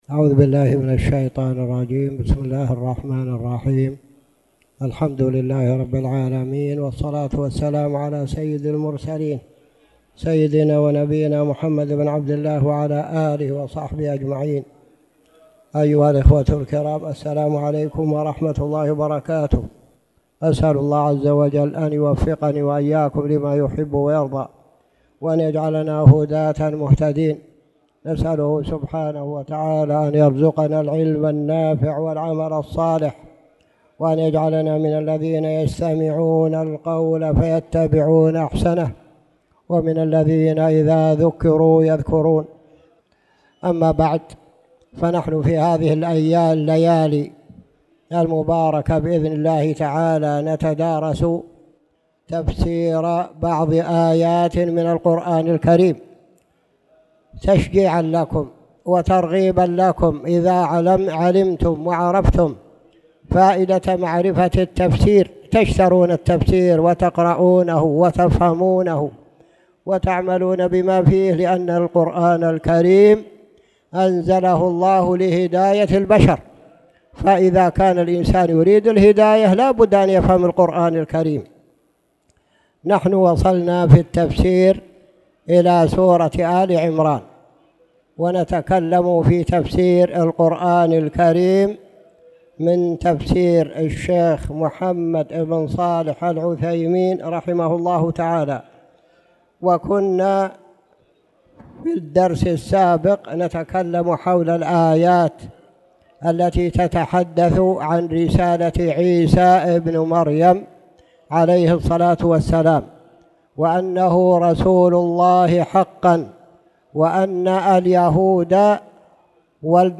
تاريخ النشر ٢٨ جمادى الأولى ١٤٣٨ هـ المكان: المسجد الحرام الشيخ